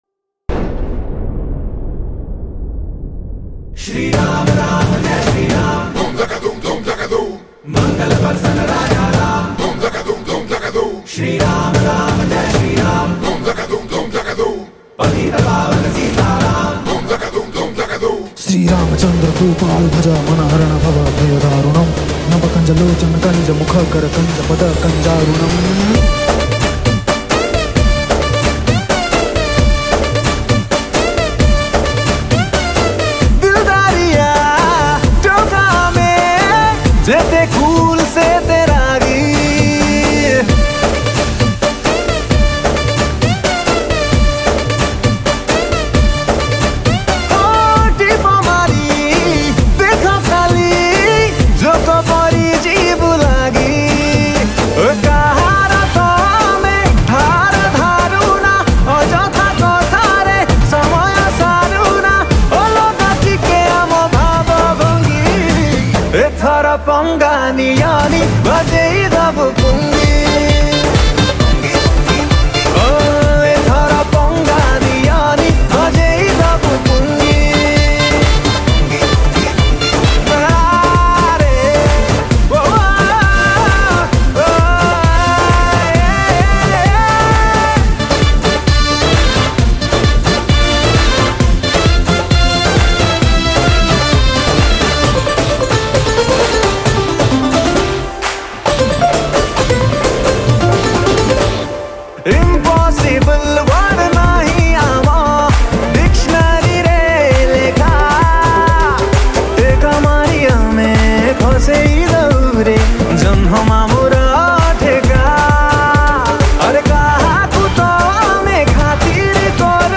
Odia Movie Song